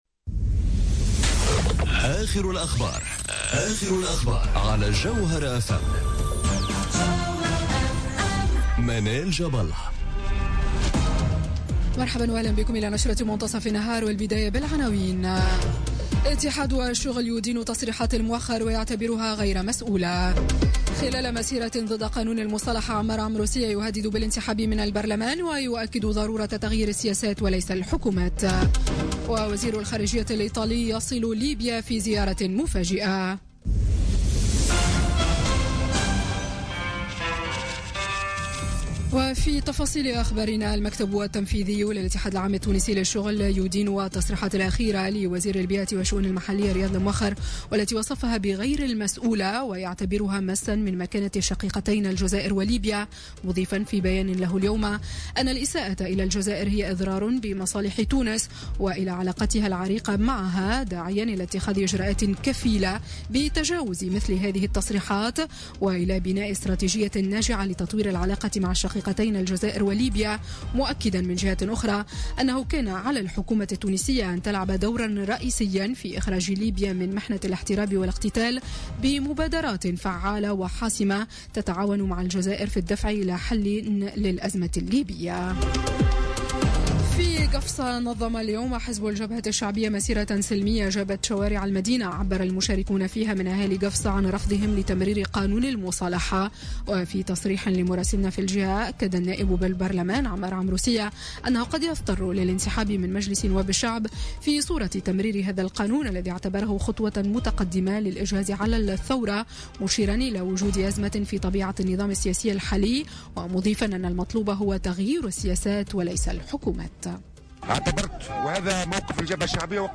نشرة أخبار منتصف النهار ليوم السبت 6 ماي 2017